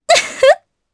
Epis-Vox_Happy1_jp.wav